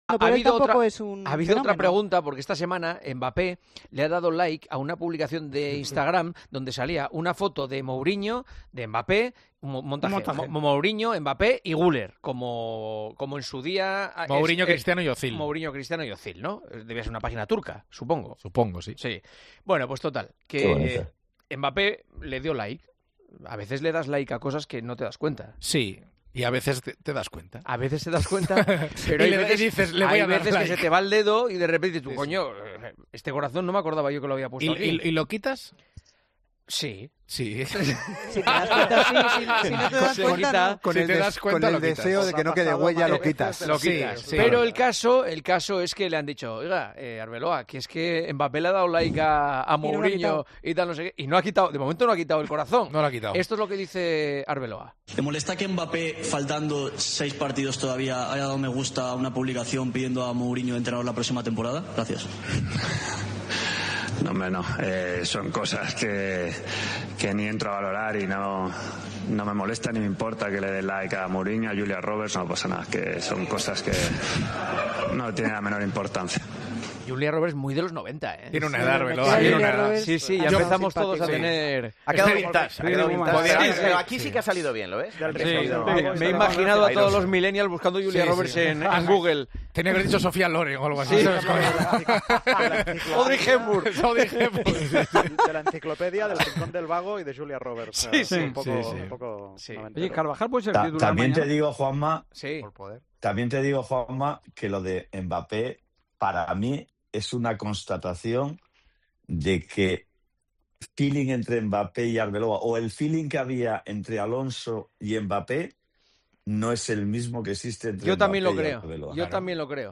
Juanma Castaño analiza con los tertulianos de El Partidazo de COPE el 'like' de Mbappé a Mourinho